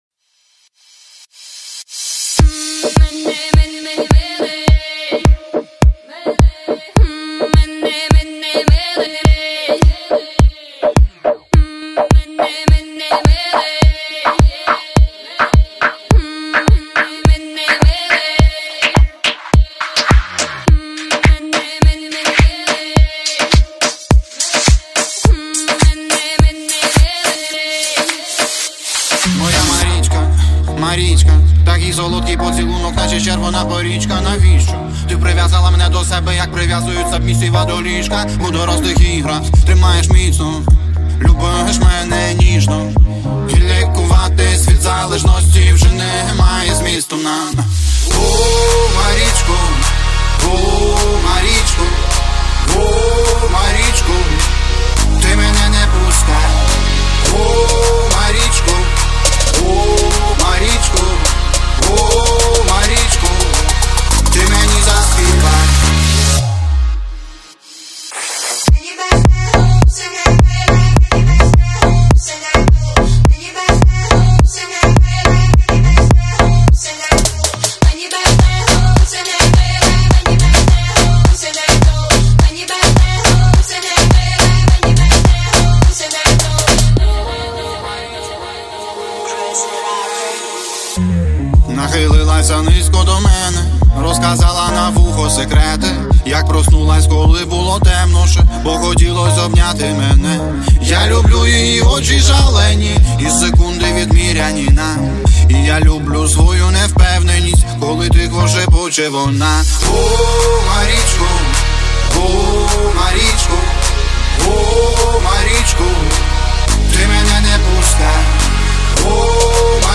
Топ реміксів на українські пісні різних жанрів та настроїв!